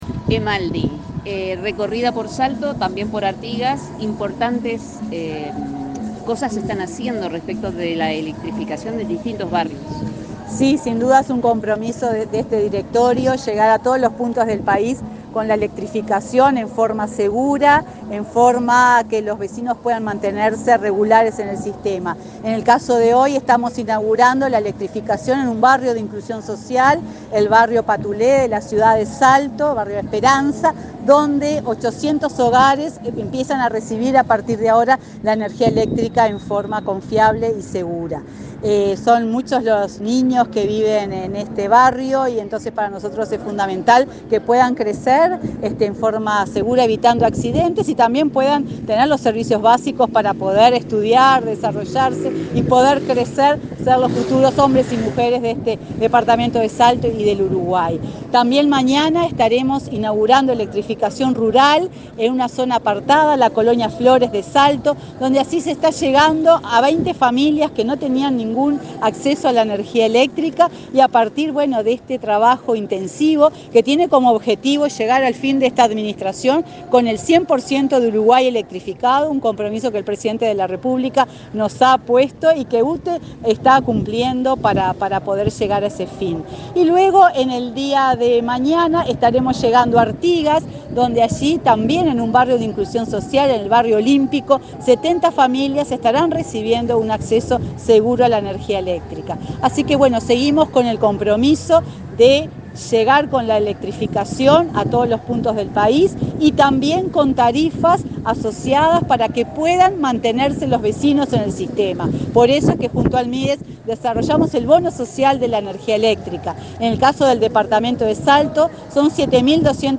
Entrevista a la presidenta de UTE, Silvia Emaldi
Tras el evento, Emaldi efectuó declaraciones a Comunicación Presidencial.